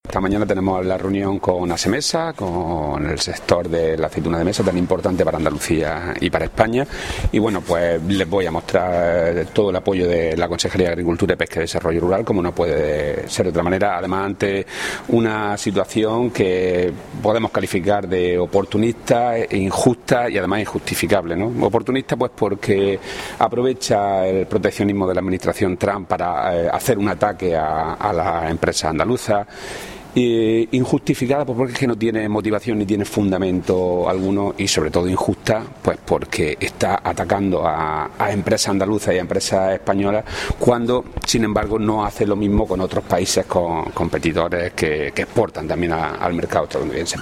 Declaraciones consejero exportaciones aceituna